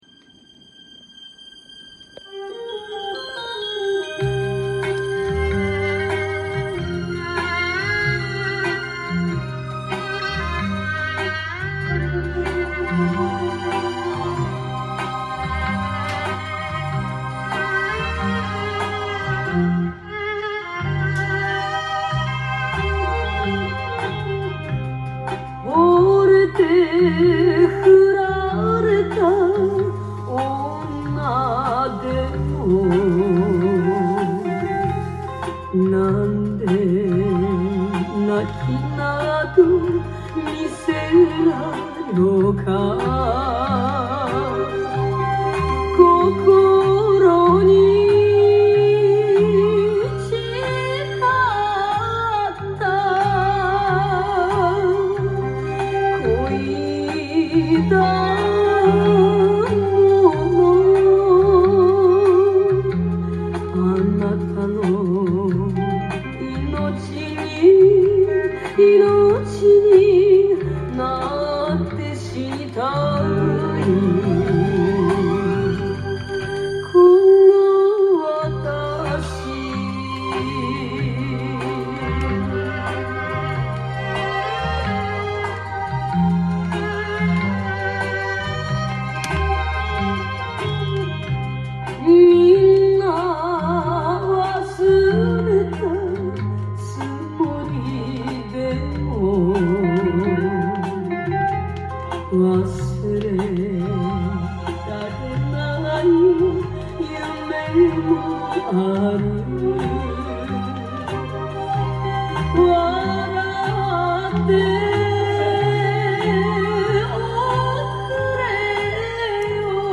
店頭で録音した音源の為、多少の外部音や音質の悪さはございますが、サンプルとしてご視聴ください。
音が稀にチリ・プツ出る程度